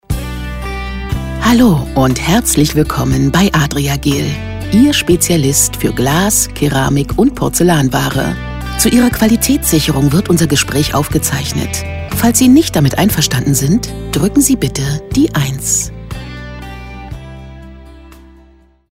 • Stimmcharakter: weich, liebevoll, freundlich, warmherzig, gefühlvoll, fein